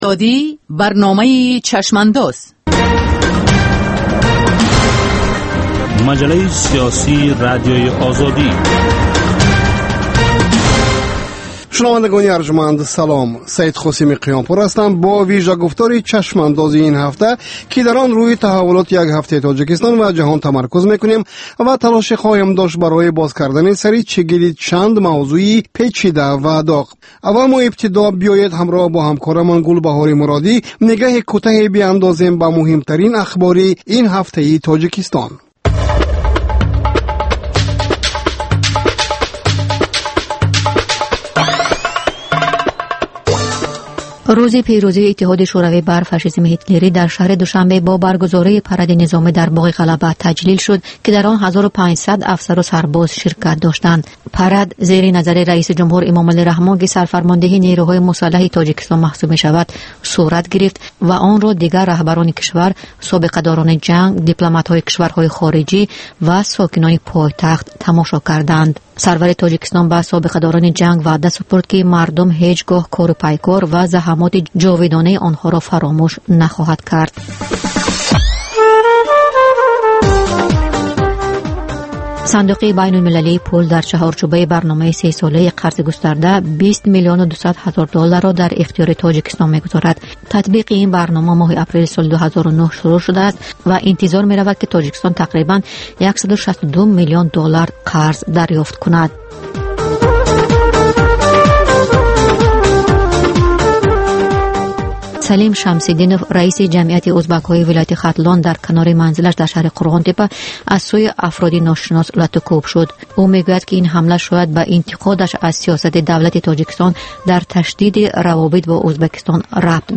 Нигоҳе ба таҳаввулоти сиёсии Тоҷикистон, минтақа ва ҷаҳон дар ҳафтае, ки гузашт. Гуфтугӯ бо сиёсатмадорон ва коршиносон.